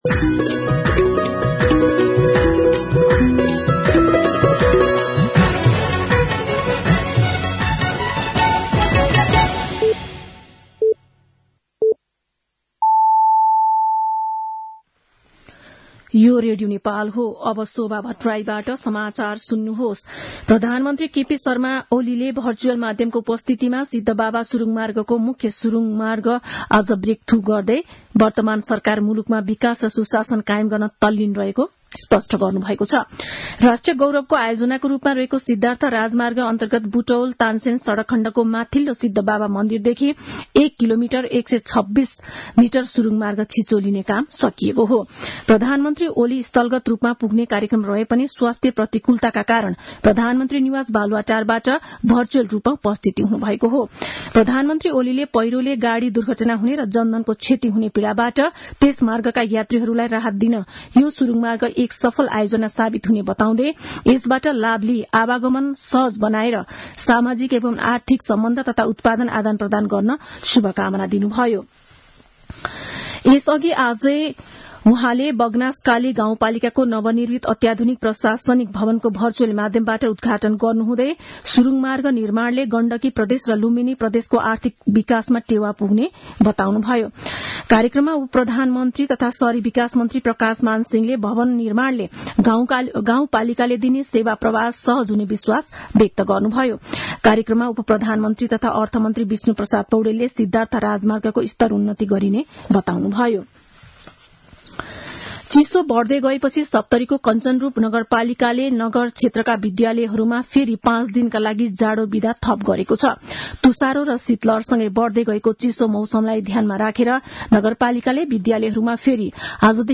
दिउँसो ४ बजेको नेपाली समाचार : १२ माघ , २०८१
4-pm-news.mp3